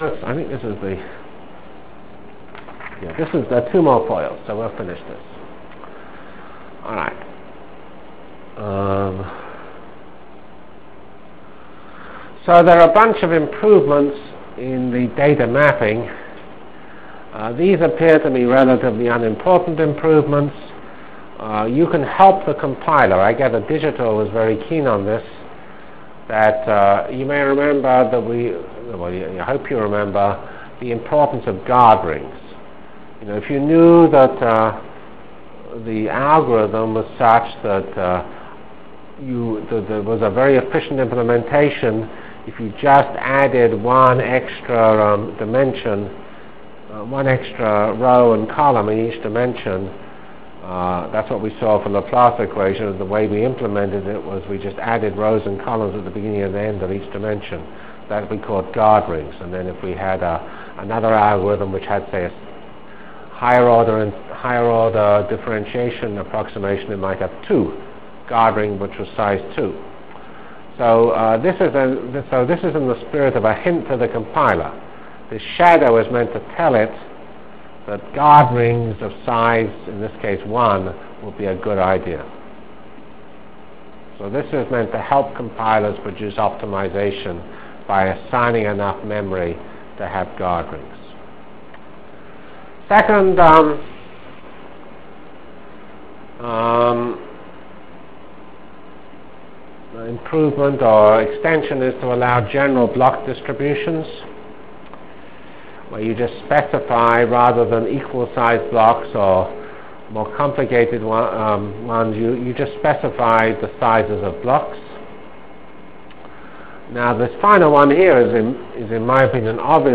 Delivered Lectures of CPS615 Basic Simulation Track for Computational Science